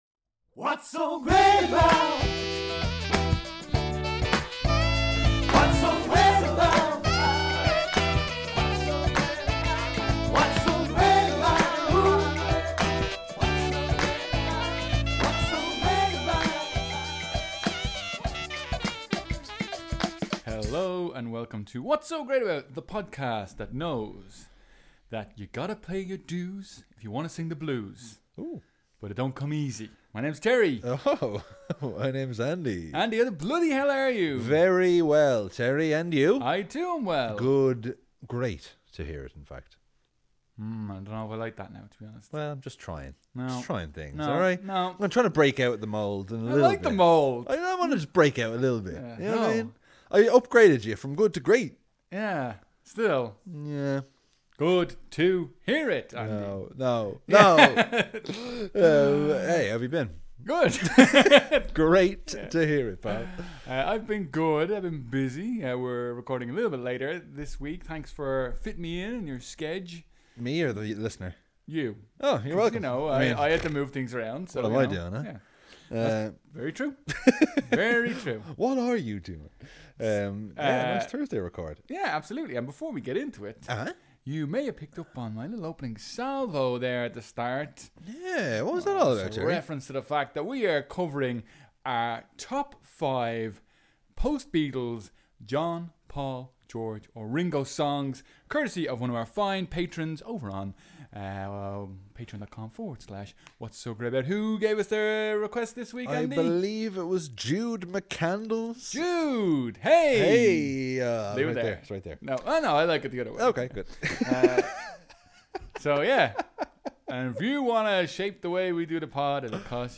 Its got laughs, its got great chats, its got music.